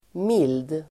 Uttal: [mil:d]